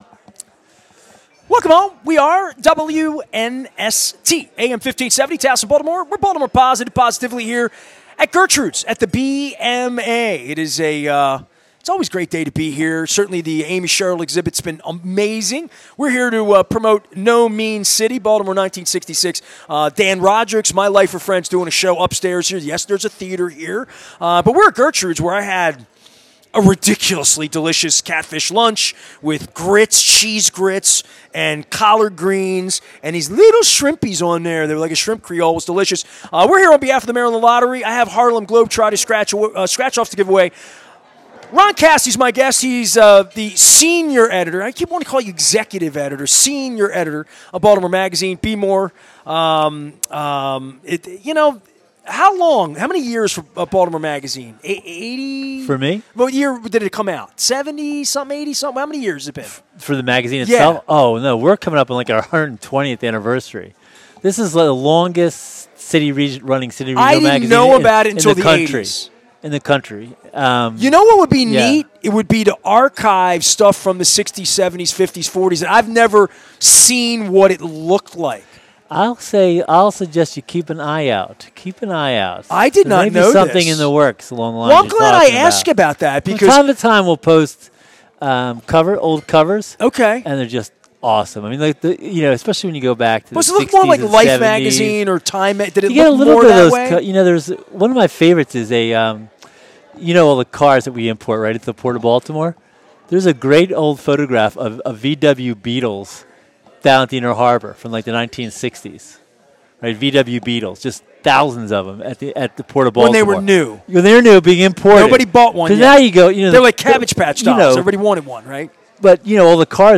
From MLB labor to The BMA art, spring into a long conversation about Pete Alonso, Frank Robinson, race and the future of the sport.